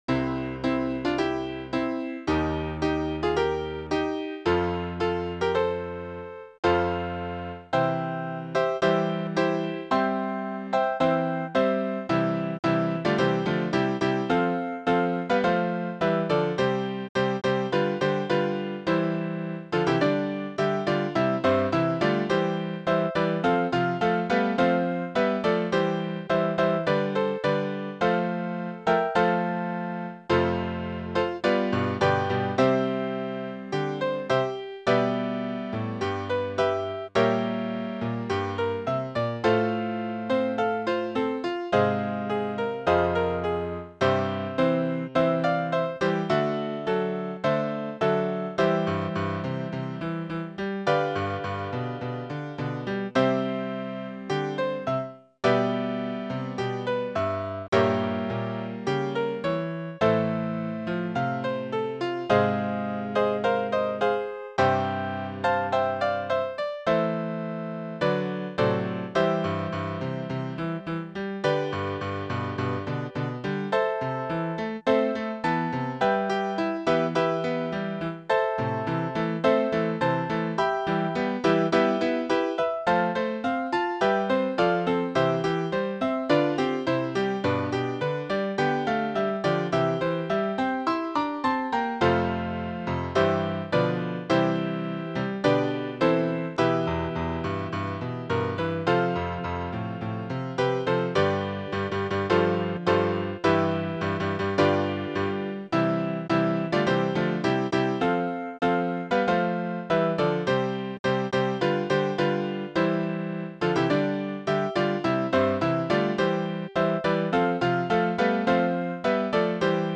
Voicing/Instrumentation: SATB , Piano Duet/Piano Ensemble